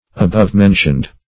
Above-mentioned \A*bove"-men`tioned\, Above-named